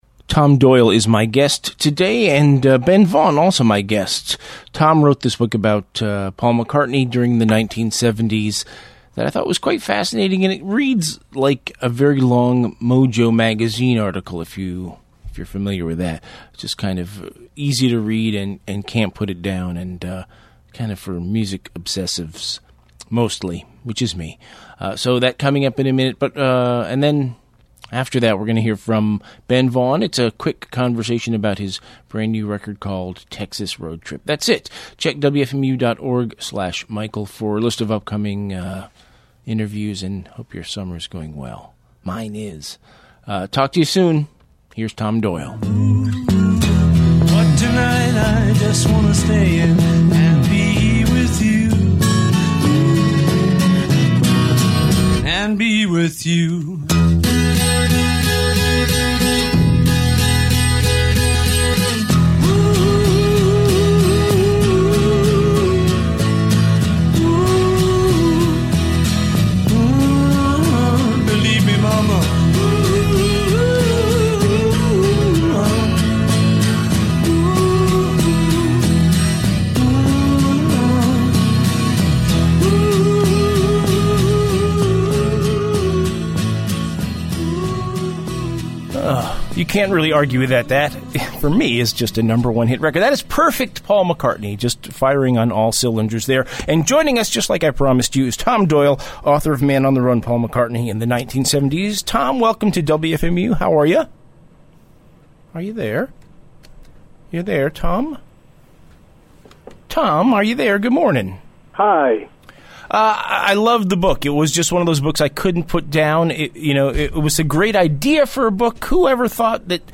Wings
INTERVIEW